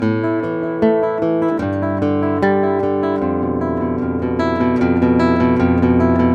The accompaniment is the open first string (E) repeated incessantly against the bass melody.
You'll produce a smoother sound than trying to fret each finger in sequence.